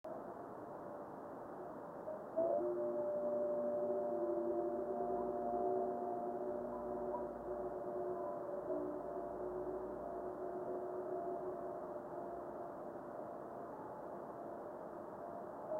Good reflection.